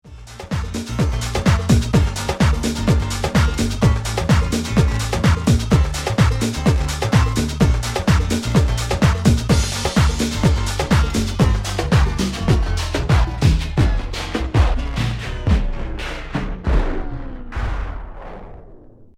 If you turn off a turntable while a record is playing, it won’t stop instantaneously but will gradually grind to a halt.
spinbacks_stops_power_offs-power_off.mp3